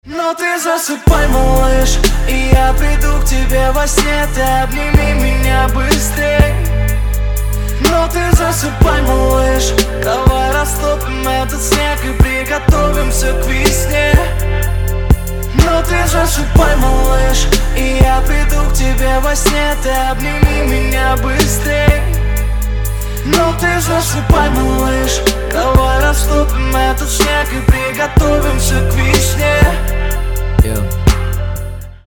Хип-хоп
спокойные
RnB